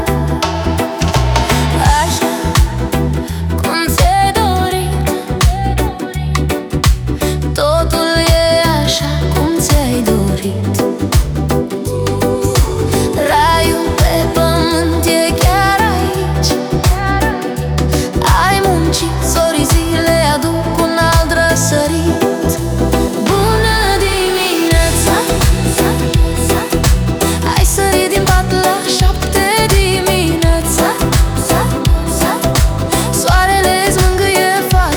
Off-beat гитары и расслабленный ритм
2025-10-20 Жанр: Регги Длительность